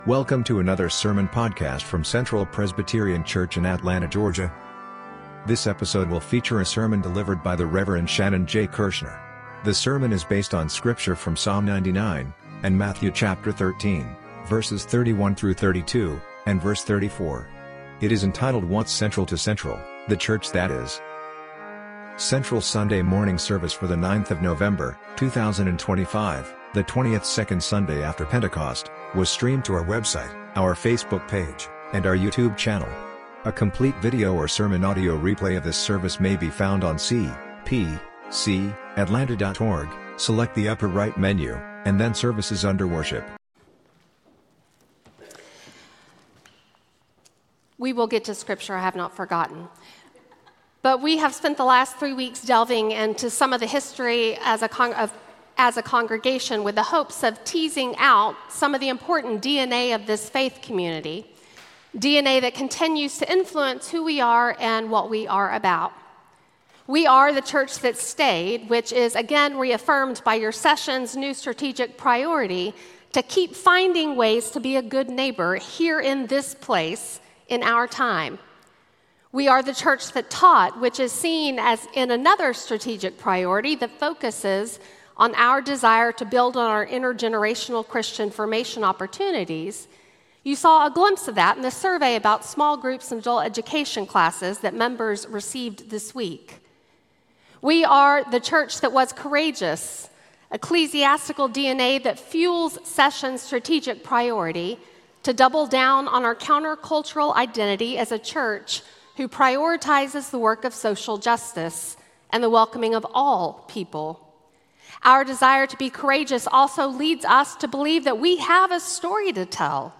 Sermon Audio:
Service Type: Sunday Sermon